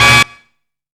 FINALE STAB.wav